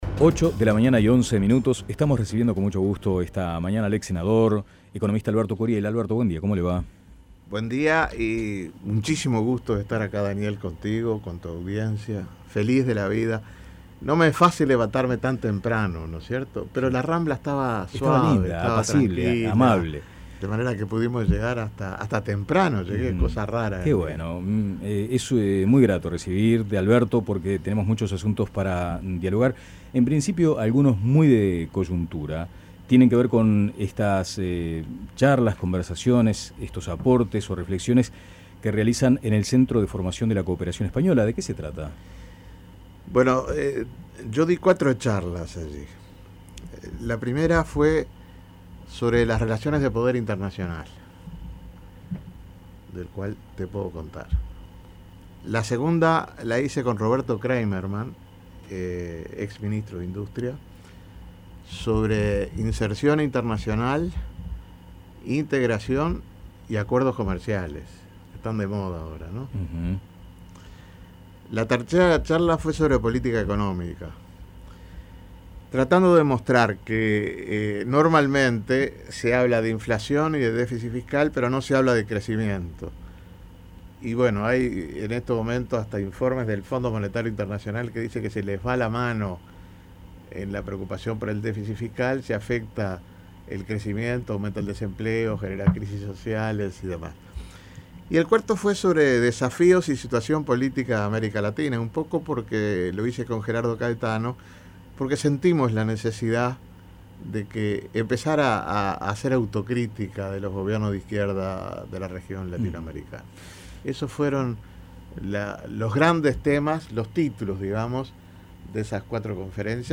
Entrevista a Alberto Curiel